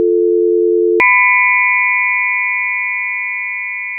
dialup.mp3